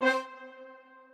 strings8_33.ogg